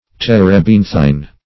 Search Result for " terebinthine" : The Collaborative International Dictionary of English v.0.48: Terebinthine \Ter`e*bin"thine\, a. [L. terbinthinus, Gr. ?.] Of or pertaining to turpentine; consisting of turpentine, or partaking of its qualities.